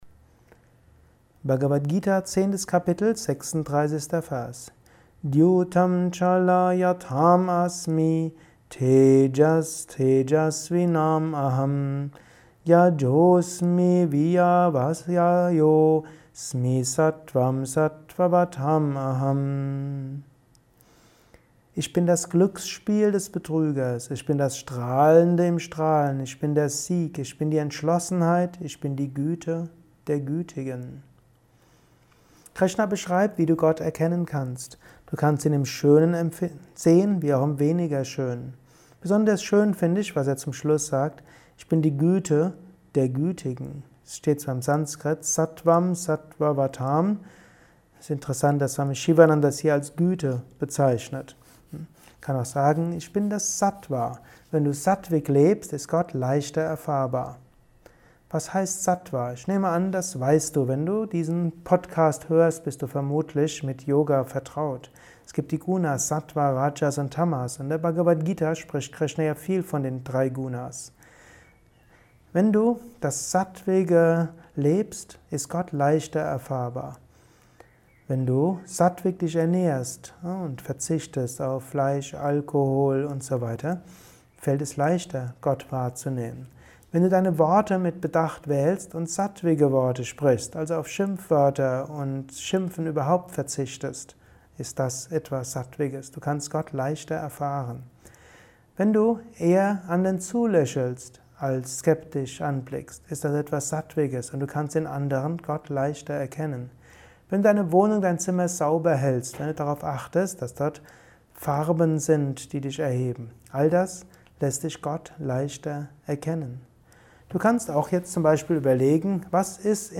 Kurzvorträge
Aufnahme speziell für diesen Podcast.